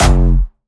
Jumpstyle Kick 4